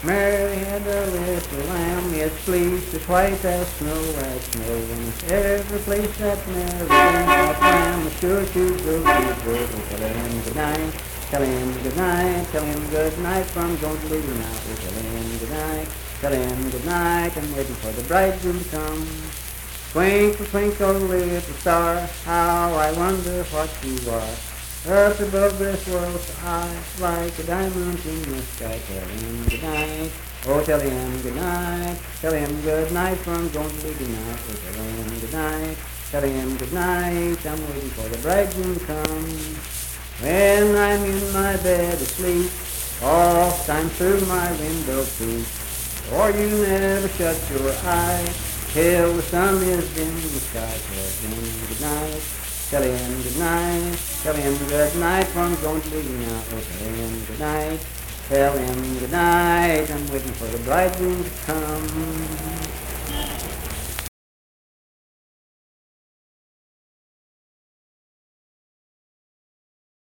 Unaccompanied vocal music performance
Children's Songs
Voice (sung)
Calhoun County (W. Va.)